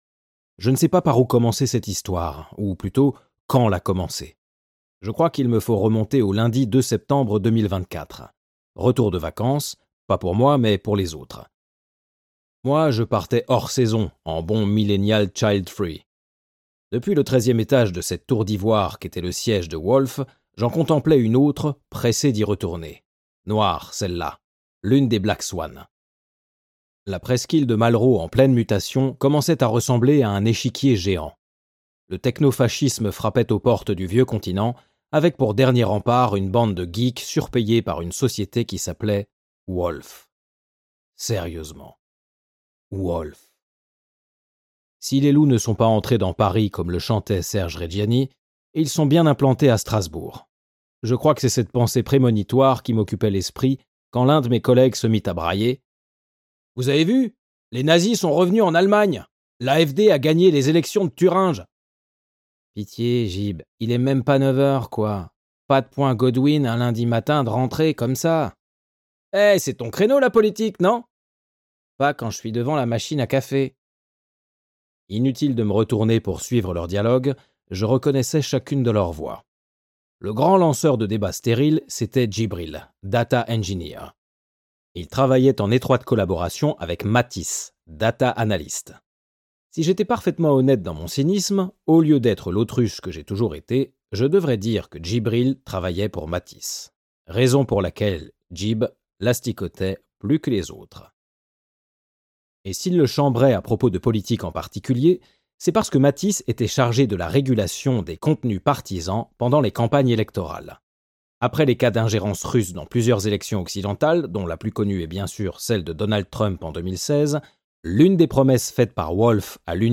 *IH ou Interprétation Humaine signifie que des comédiennes et comédiens ont travaillé à l'enregistrement de ce livre audio, et qu'aucune voix n'a été enregistrée avec l'intelligence artificielle.